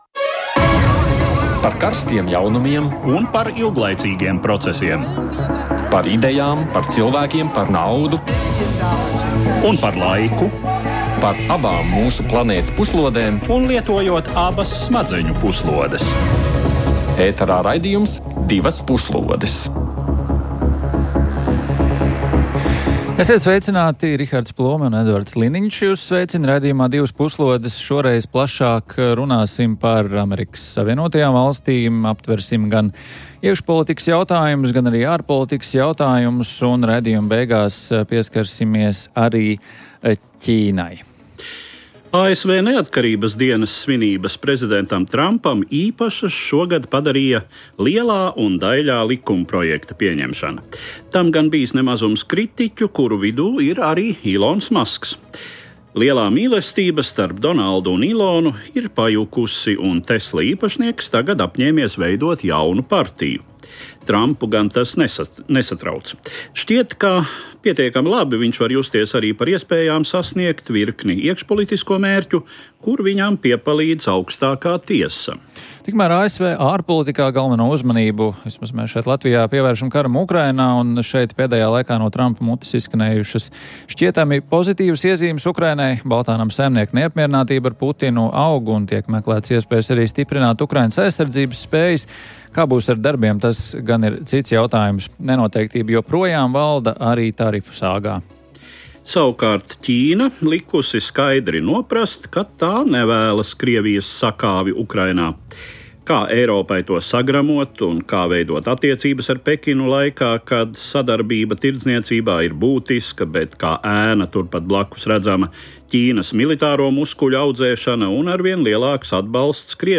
Raidījums par ārpolitikas aktualitātēm, kurā kopā ar ekspertiem un ārpolitikas pārzinātājiem apspriežam un analizējam nedēļas svarīgākos notikumus pasaulē.